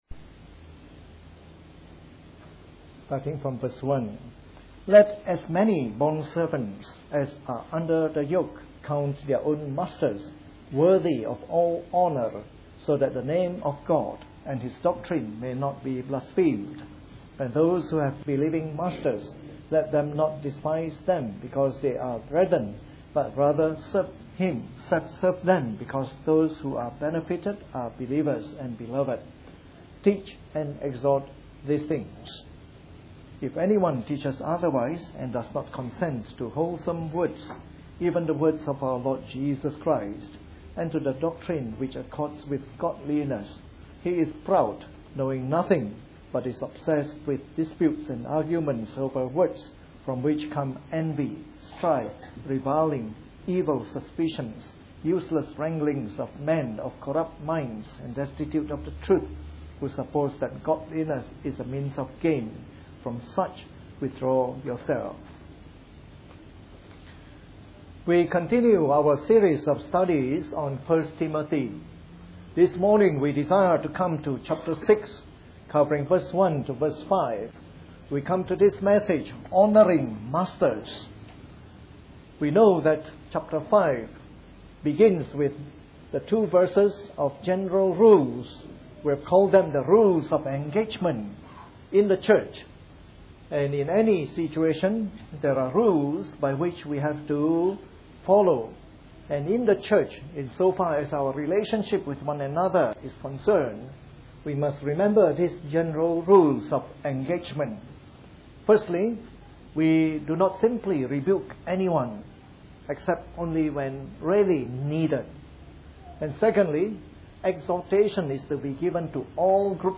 A sermon in the morning service from our series on 1 Timothy.